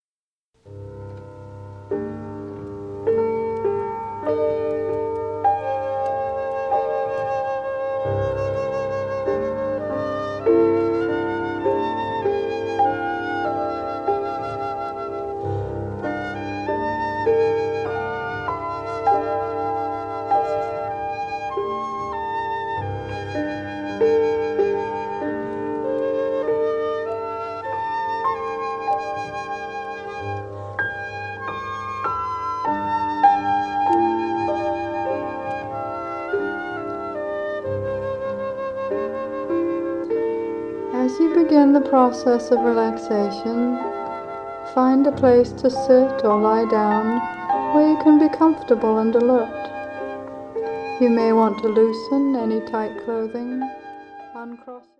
Seasons for Healing: Winter (Guided Meditation)
Piano and Synthesizer
Flute